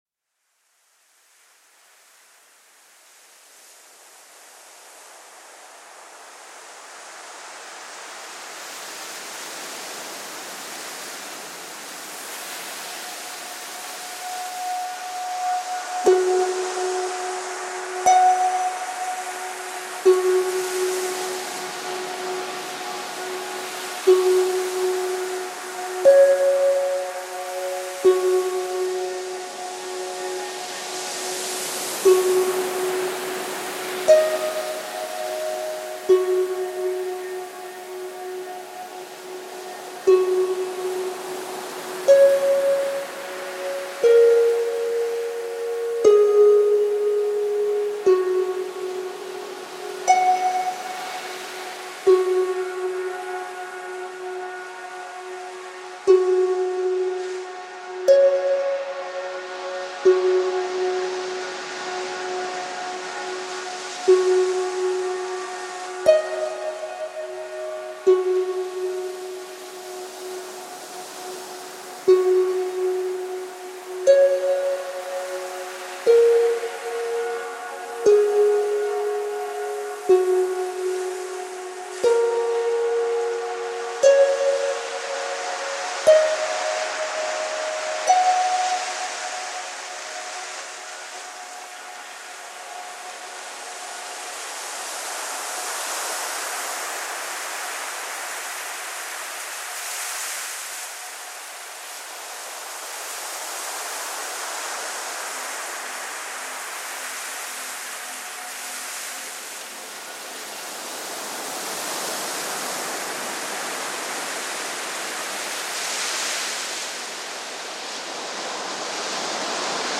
O som da sessão vai aparecendo lentamente.